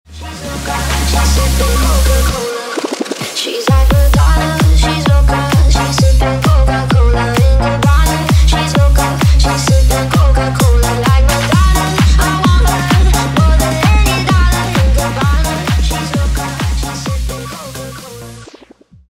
зарубежные клубные